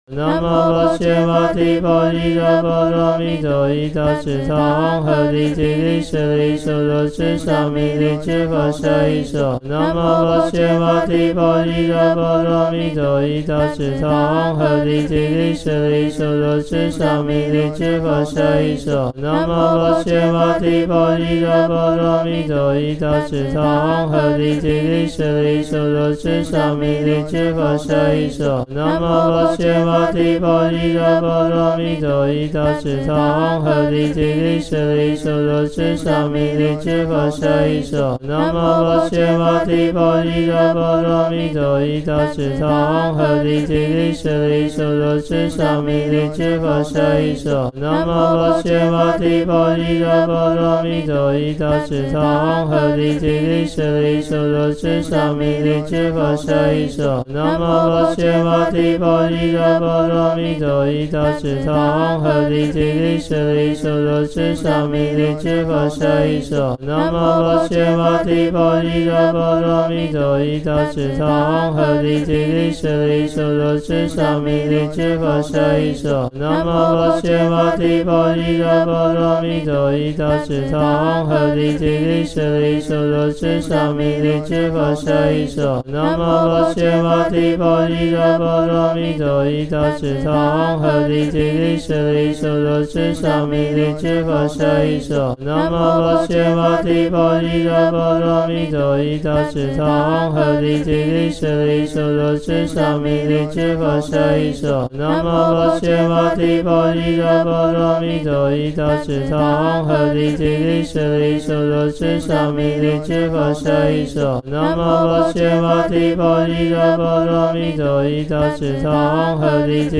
佛教音樂網》Buddhism Music